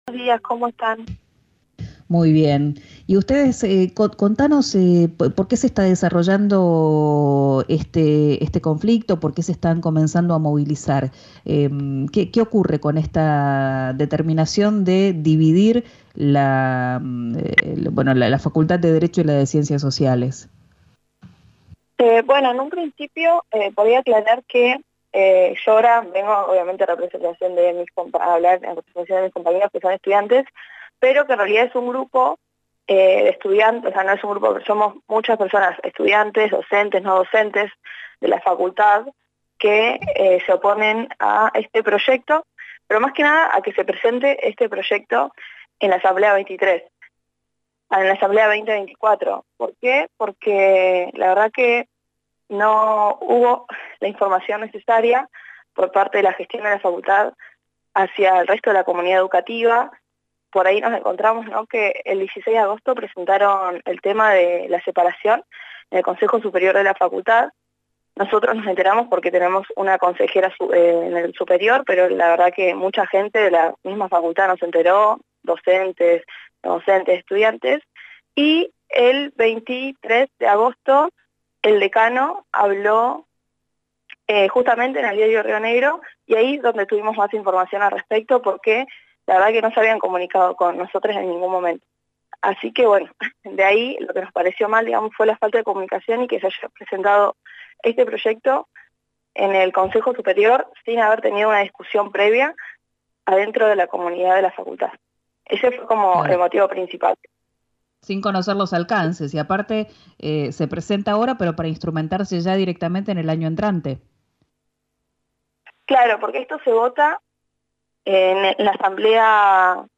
En una entrevista exclusiva de RÍO NEGRO RADIO